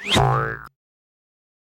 blink.wav